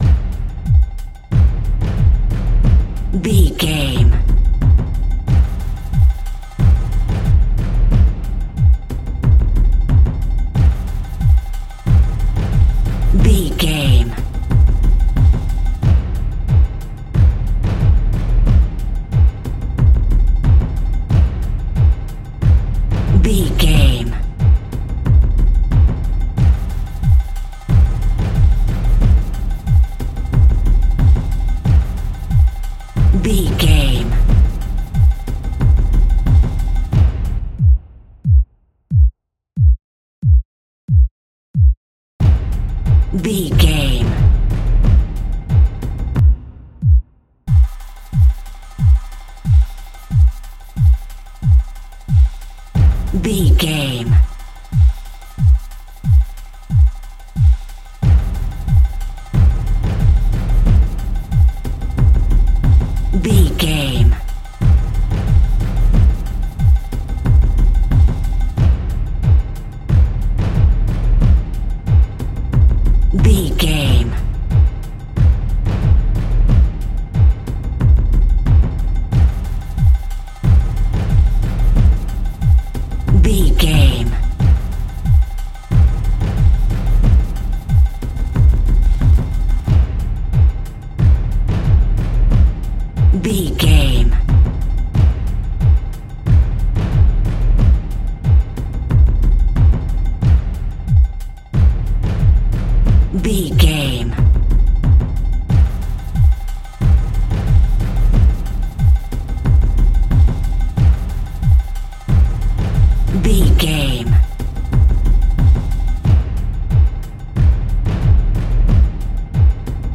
Atonal
Fast
World Music
ethnic percussion